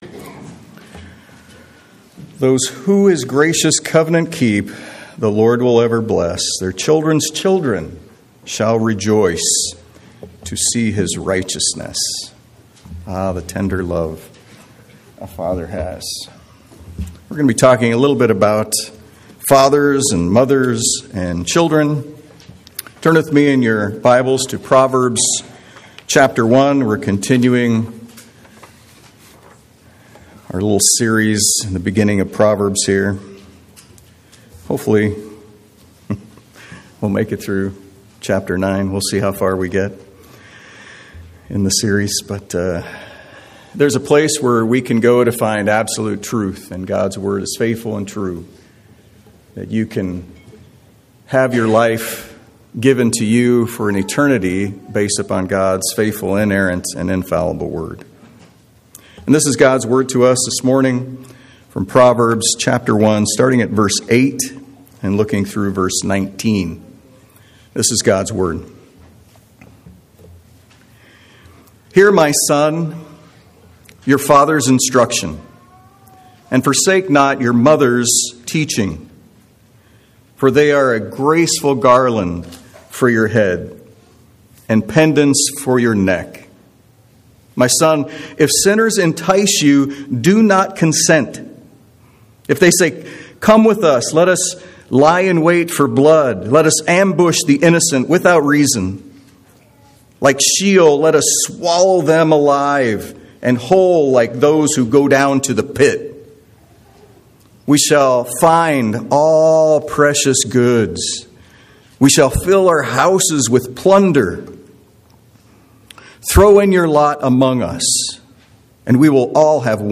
Unfortunately, we were only able to record the first 10 minutes of this sermon.